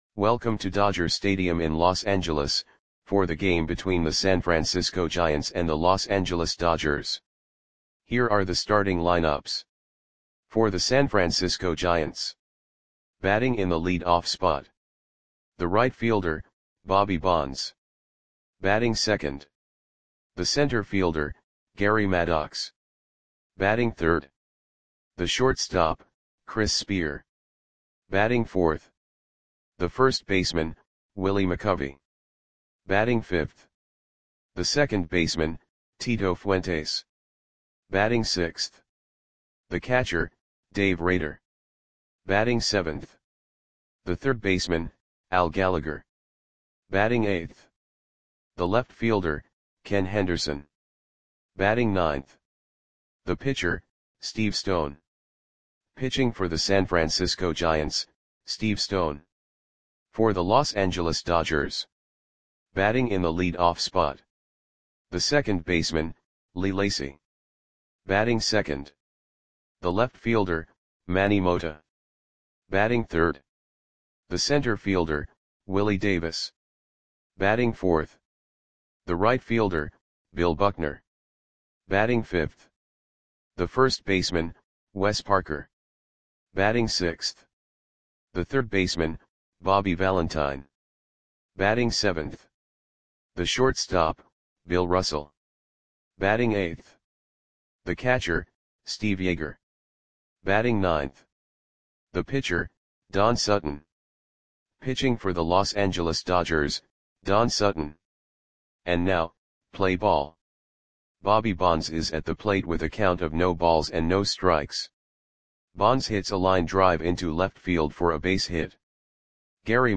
Click the button below to listen to the audio play-by-play.